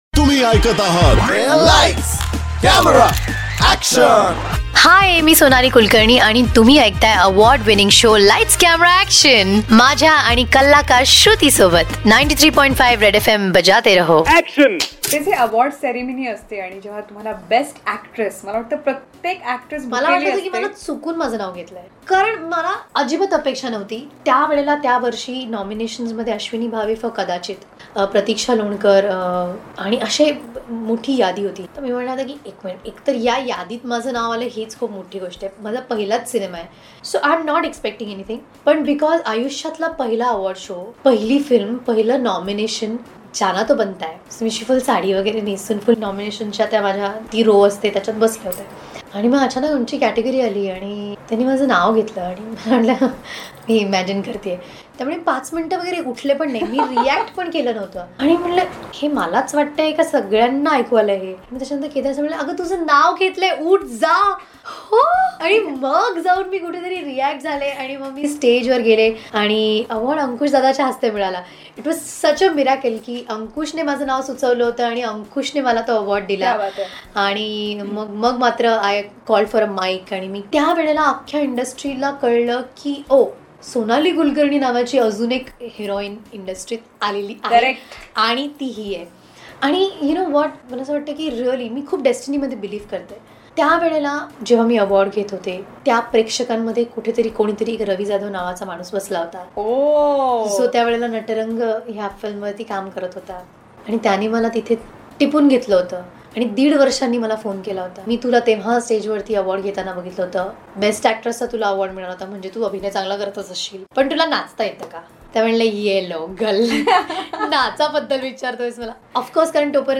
CONVERSATION WITH SONALEE KULKARNI PART 4